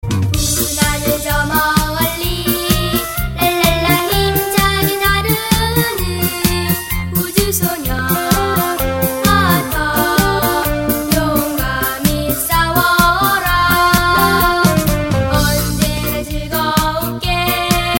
벨소리